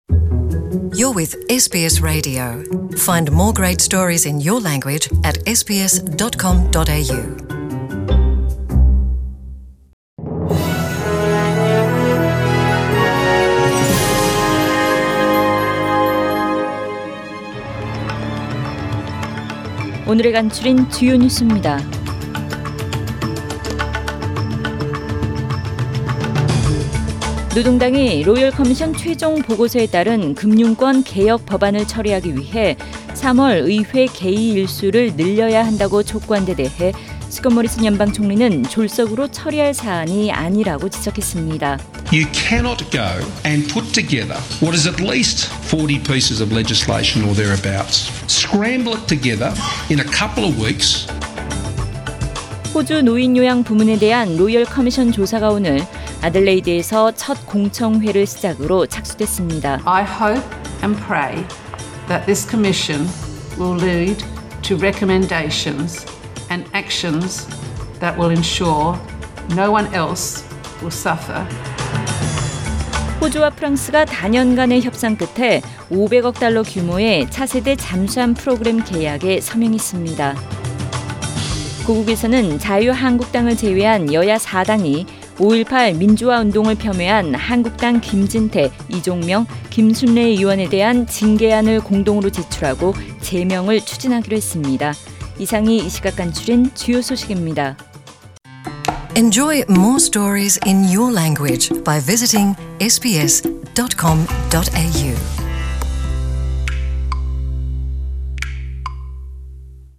2019년 2월 11일 월요일 저녁의 SBS Radio 한국어 뉴스 간추린 주요 소식을 팟 캐스트를 통해 접하시기 바랍니다.